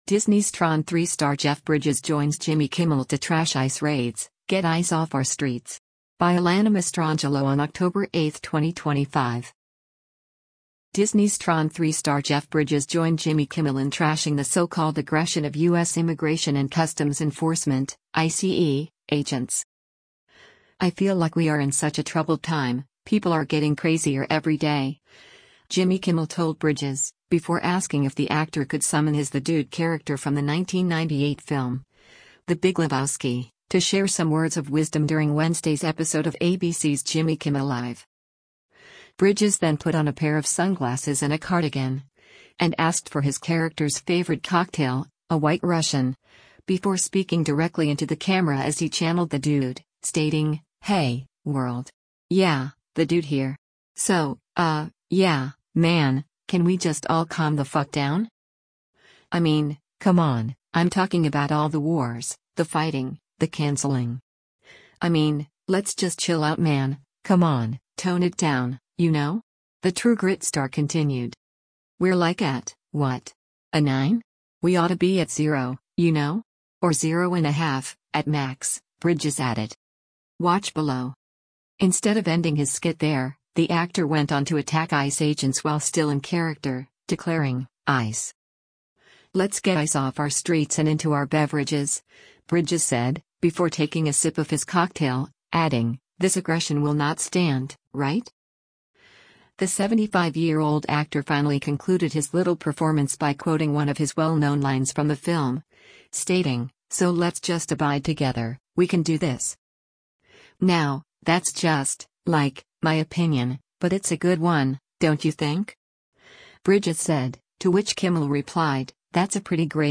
Bridges then put on a pair of sunglasses and a cardigan, and asked for his character’s favorite cocktail — a White Russian — before speaking directly into the camera as he “channeled” The Dude, stating, “Hey, world! Yeah, The Dude here. So, uh, yeah, man, can we just all calm the fuck down?”
The two men then clinked their glass together for a toast.